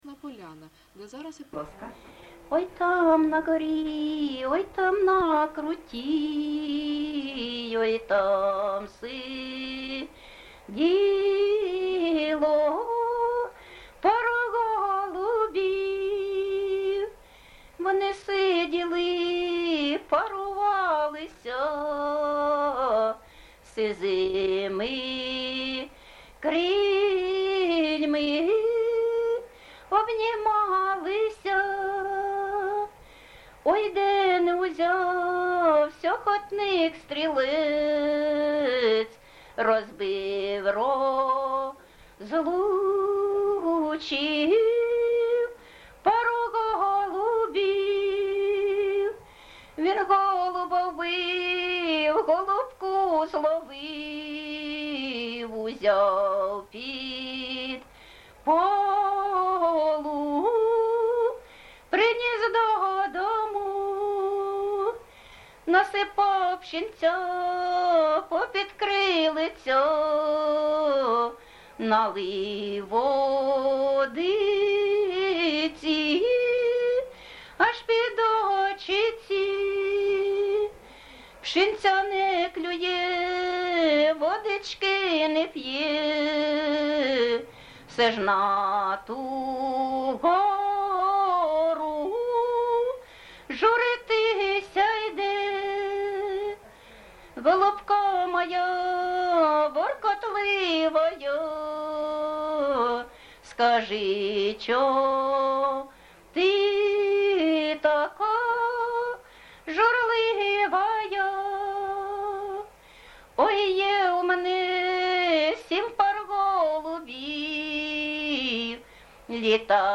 ЖанрПісні з особистого та родинного життя, Балади
Місце записус-ще Ясна Поляна, Краматорський район, Донецька обл., Україна, Слобожанщина